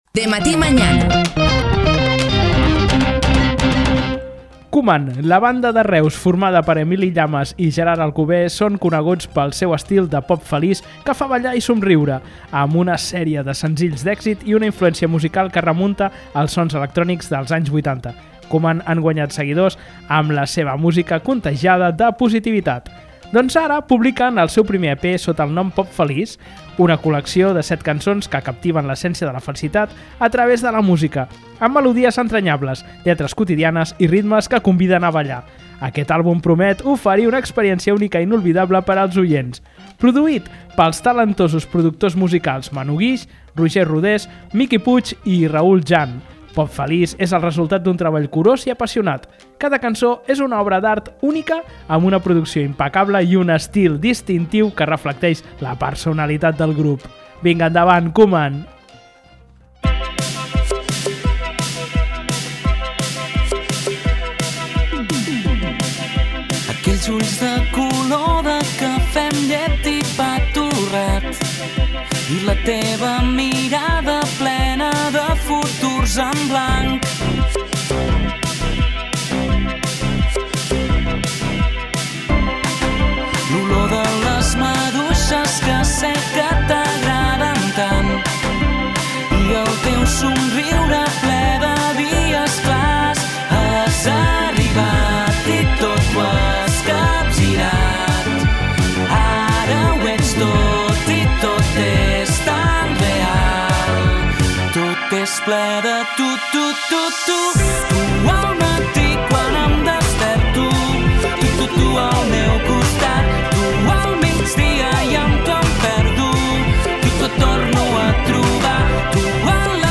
són coneguts pel seu estil de pop feliç que fa ballar i somriure. Amb una sèrie de senzills d’èxit i una influència musical que es remunta als sons electrònics dels anys 80, Koeman han guanyat seguidors amb la seva música contagiada de positivitat.
Amb melodies entranyables, lletres quotidianes i ritmes que conviden a ballar, aquest àlbum promet oferir una experiència única i inoblidable per als oients.